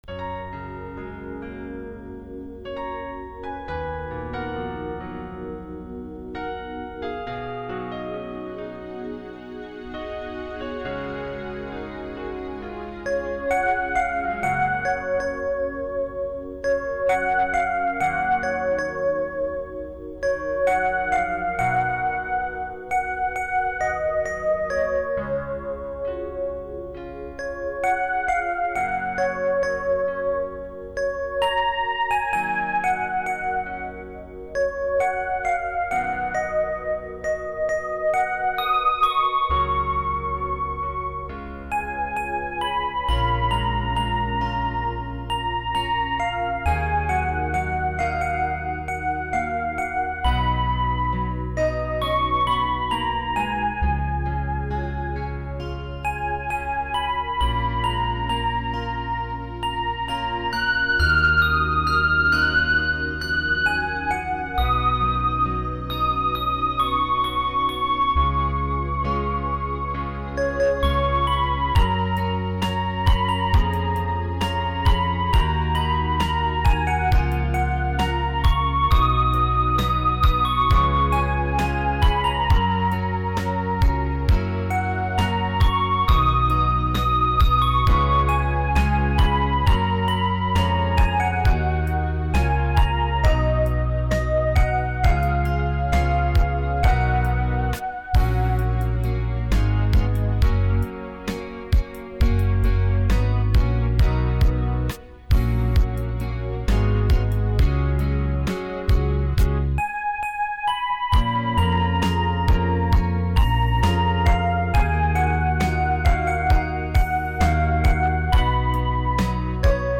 按下打击垫切换pattern的时候，U-Key会自动等待下一个小节切换，保证节奏不会乱。
每个打击垫依次触发不同pattern
下载U-Key内置的一段乐曲，依次触发1-8这8段pattern